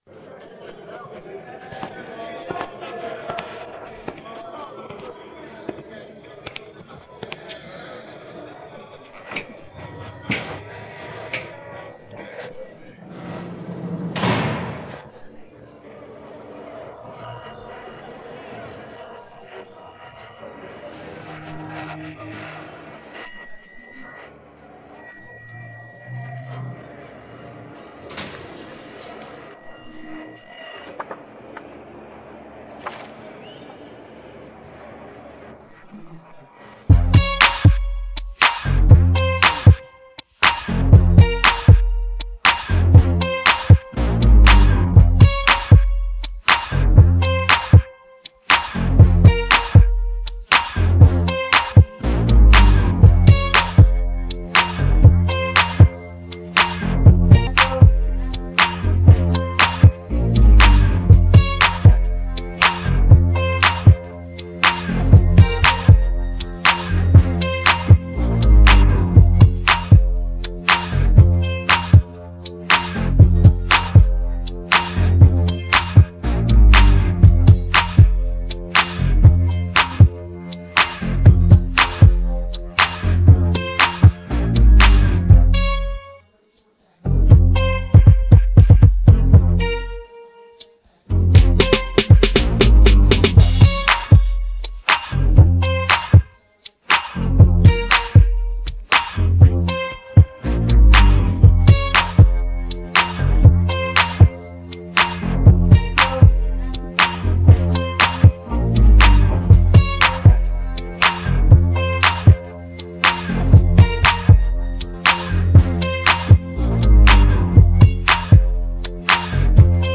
Pop R&B